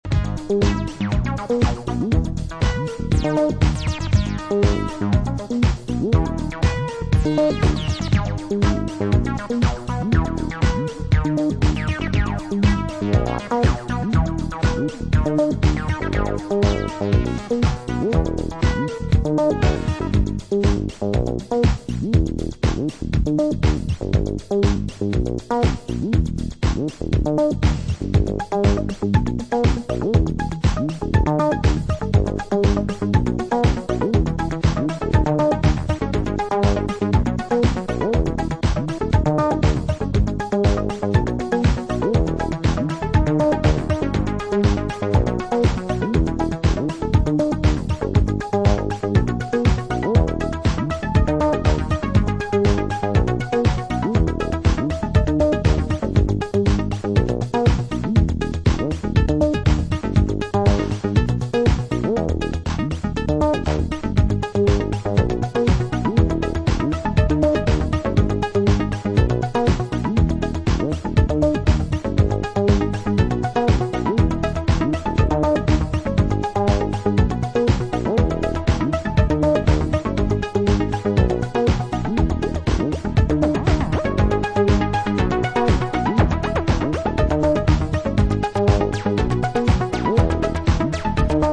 analogue synths, warm melodies and experimental electro
Electro